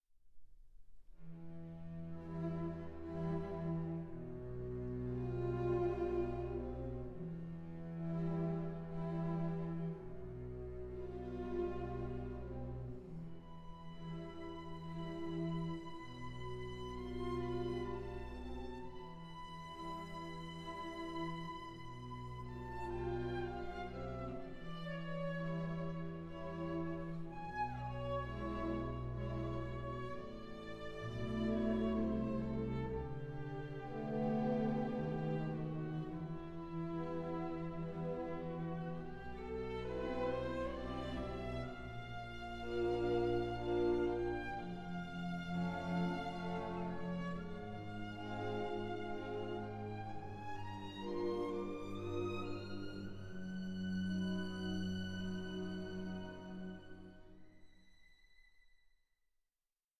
Lento 7:46